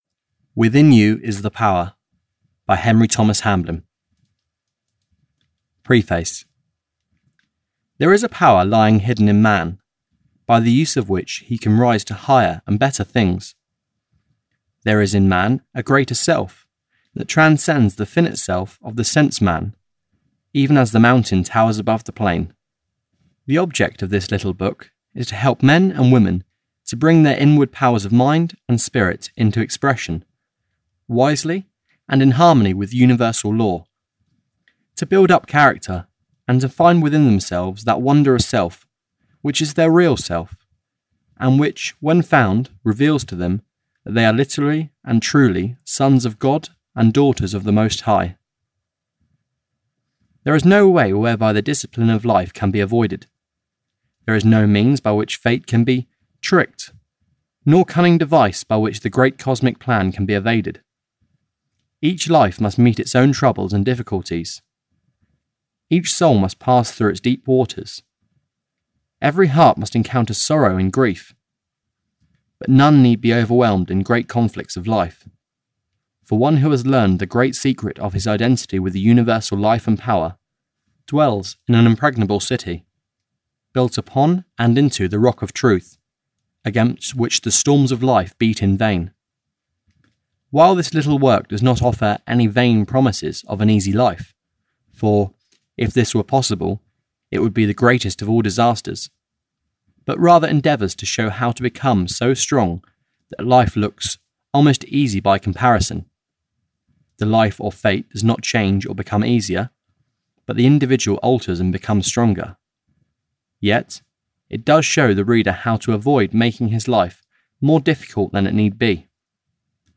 Within You Is The Power (EN) audiokniha
Ukázka z knihy